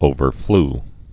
(ōvər-fl)